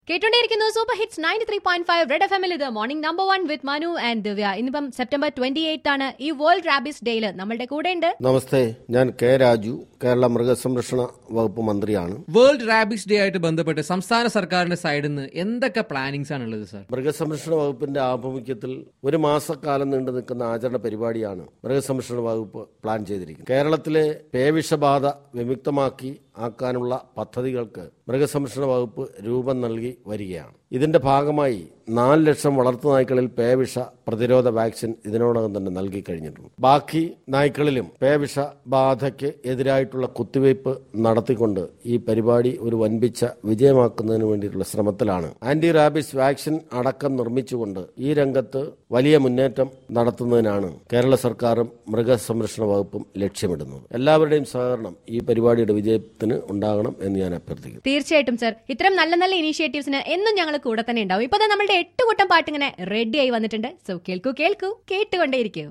CHAT WITH K. Raju-Minister of Forest, Animal Husbandry and Zoos